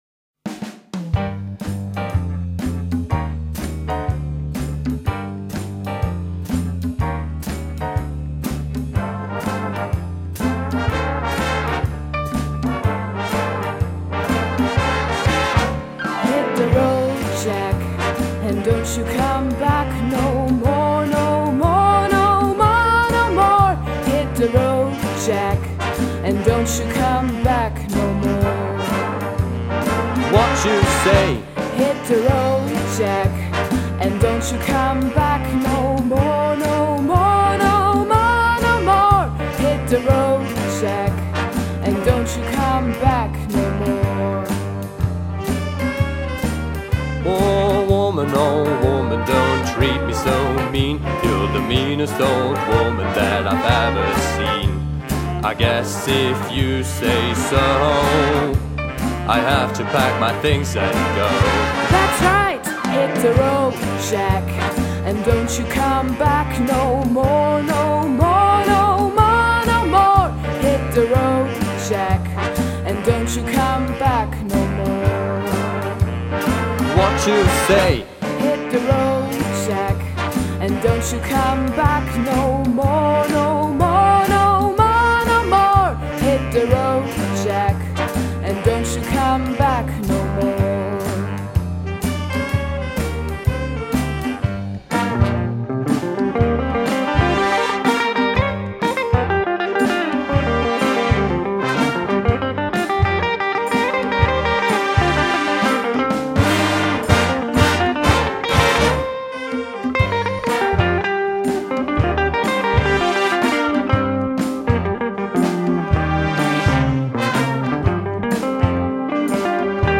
Den Instrumentalisten stehen drei verschiedene AGs unterschiedlicher Stilrichtungen zur Verfügung: Es gibt ein Streicher-Ensemble, das große sinfonischen Blasorchester und die vollbesetzte groovige Big Band.
Hörbeispiel 02 der JVG Big Band: Hit the Road
Jack (mit Gesang)
02-Hit-the-Road-Jack-Gesang.mp3